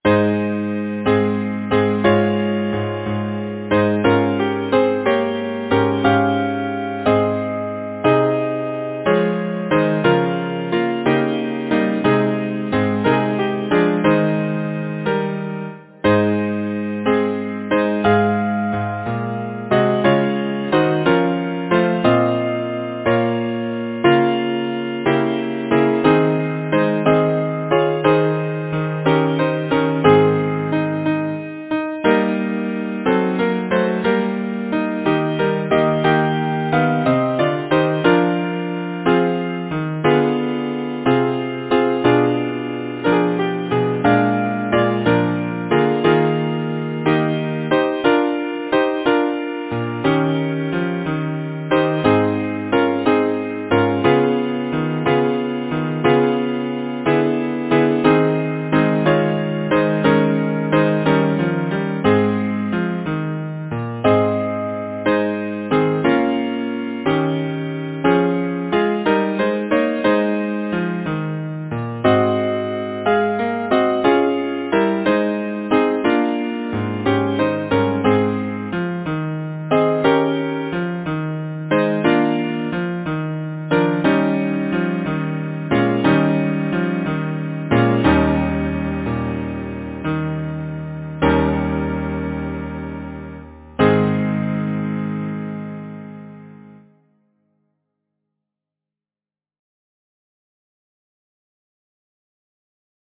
Title: Sleep, Gentle Babe Composer: Henry Thomas Smart Lyricist: James Steven Stallybrass Number of voices: 4vv Voicing: SATB Genre: Secular, Partsong
Language: English Instruments: A cappella